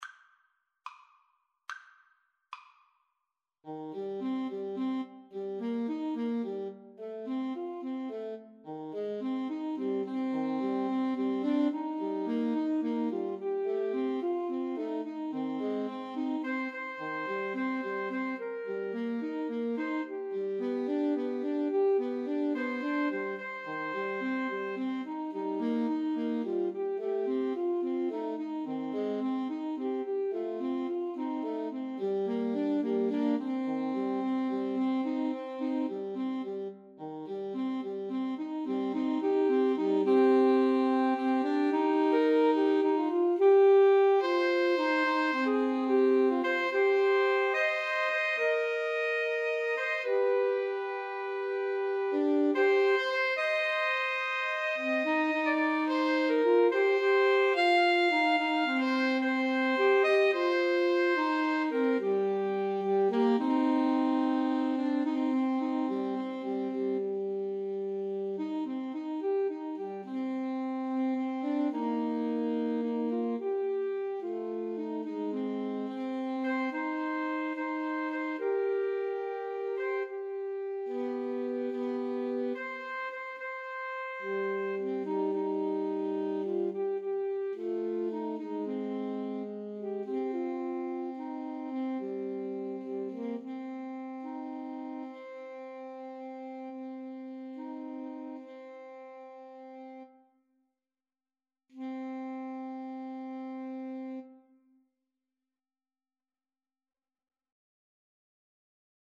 Alto Saxophone 1Alto Saxophone 2Tenor Saxophone
Gently . = c.72
6/8 (View more 6/8 Music)
Pop (View more Pop 2-Altos-Tenor-Sax Music)